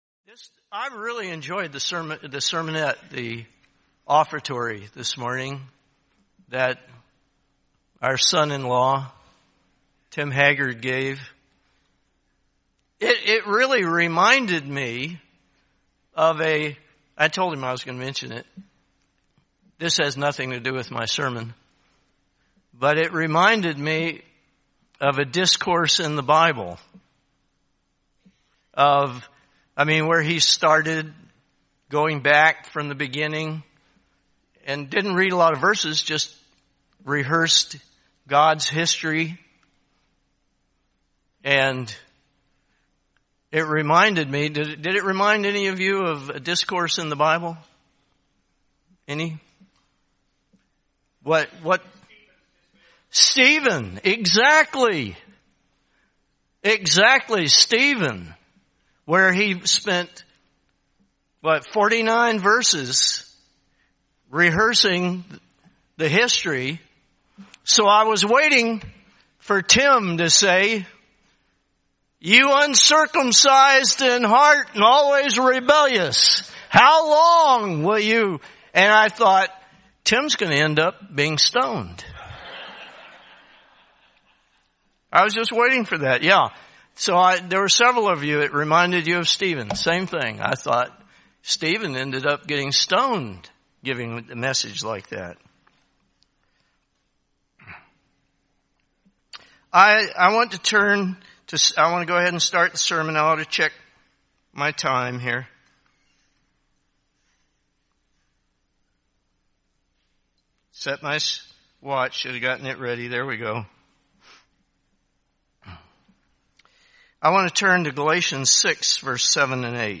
A Pentecost message diving into a scriptural admonition to use every moment to take action in developing the fruit of the Spirit.